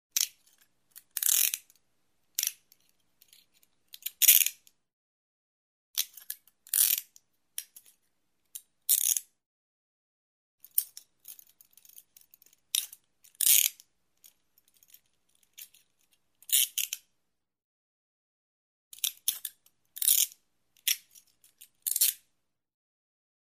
На этой странице собраны различные звуки наручников: от звонкого удара металла до характерного щелчка замка.
Звук защелкивания наручников на запястьях — вариант 2